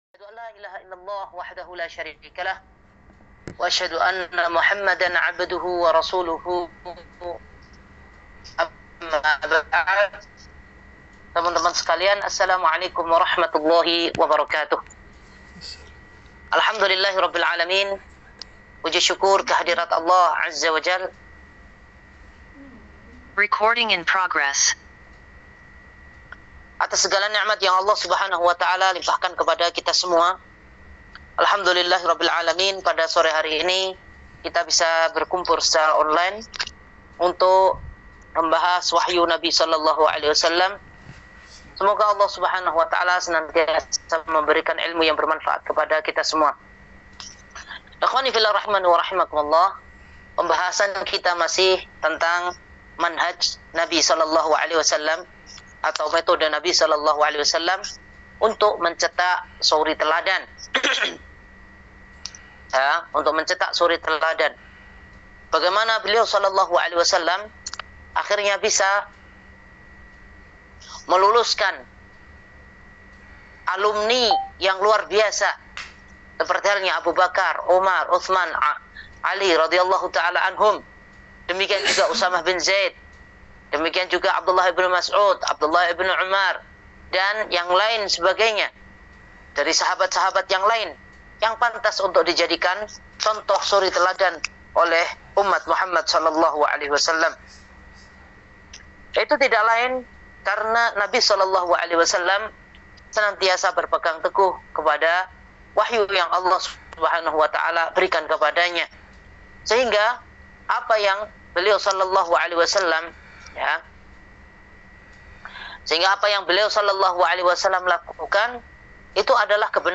Kajian Online